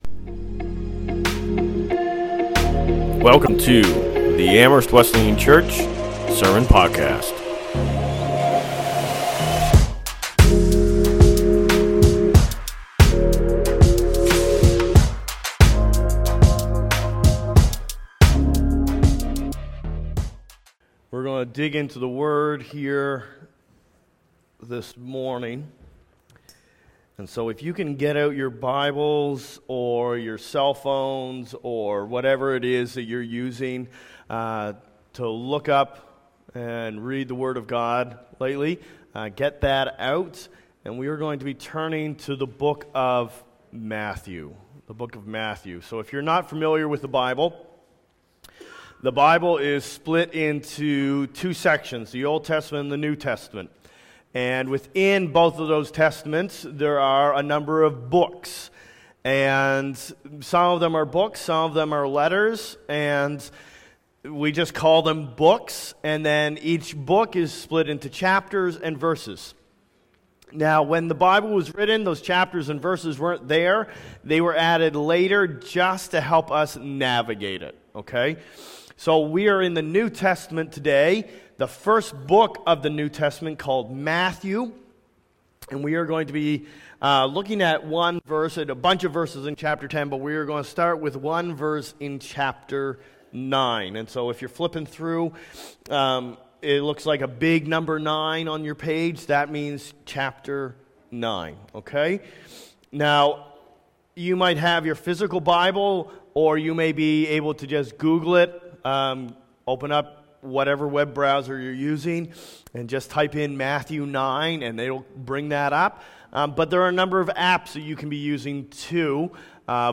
Sermons | Amherst Wesleyan Church